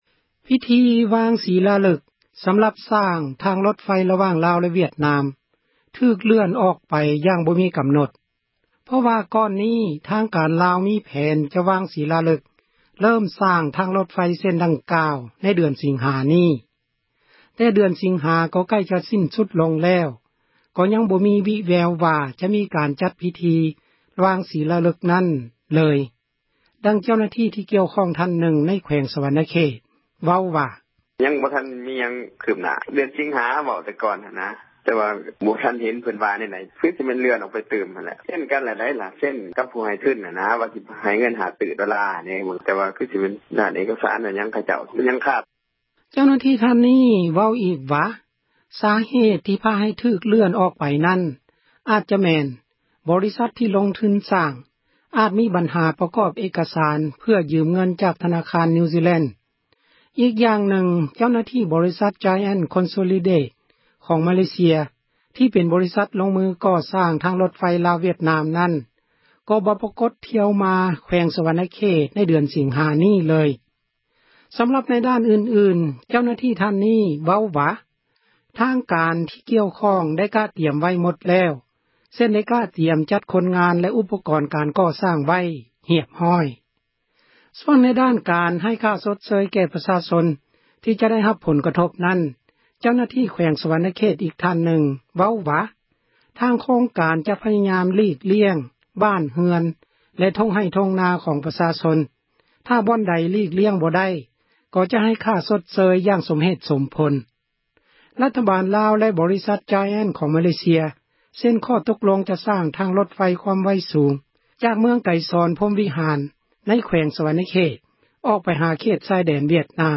ພິທີວາງສີລາລຶກ ສ້າງທາງຣົດໄຟ ຣະຫວ່າງ ລາວຫ-ວຽດນາມ ຖືກເລື່ອນອອກໄປ ຢ່າງບໍ່ມີກຳນົດ ເພາະວ່າກ່ອນນີ້ ທາງການລາວ ມີແຜນ ຈະວາງສີລາລຶກ ເລິ້ມ ສ້າງທາງຣົດເສັ້ນດັ່ງກ່າວ ໃນ ເດືອນສິງຫາ ນີ້ ແຕ່ເດືອນສິງຫາ ກໍໃກ້ຈະສິ້ນສຸດລົງແລ້ວ ກໍຍັງບໍ່ມີວິແວວວ່າ ຈະມີ ການຈັດພິທີ ນັ້ນເລີຍ. ດັ່ງເຈົ້າໜ້າທີ່ ທ່ານນຶ່ງ ໃນແຂວງສວັນນະເຂດ ເວົ້າວ່າ: